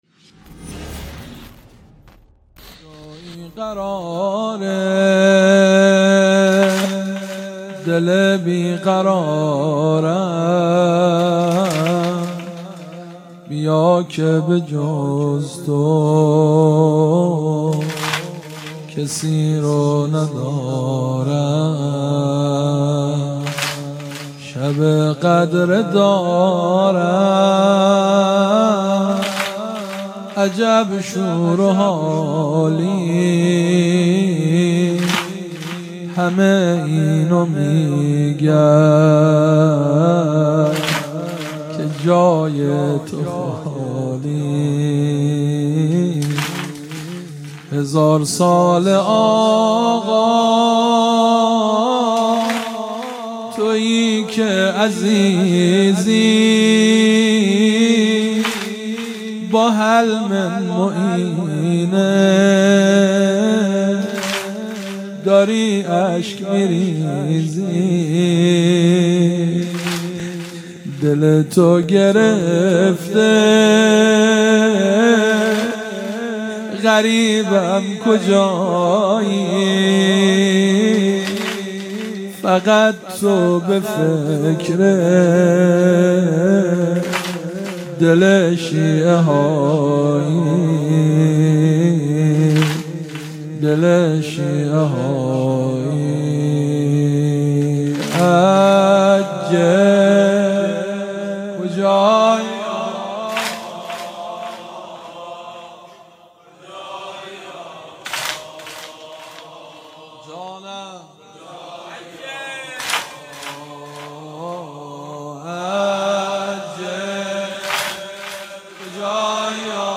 مداحی
مراسم شب قدر 97/03/13